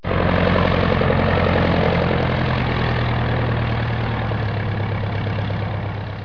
دانلود آهنگ طیاره 10 از افکت صوتی حمل و نقل
دانلود صدای طیاره 10 از ساعد نیوز با لینک مستقیم و کیفیت بالا
جلوه های صوتی